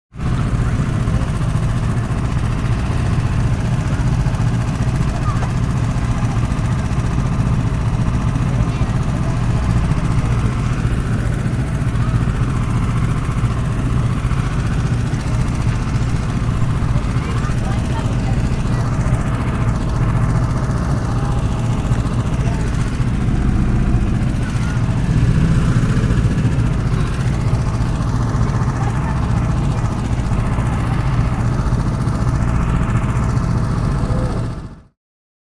Звук мотора карта на фоне голосов людей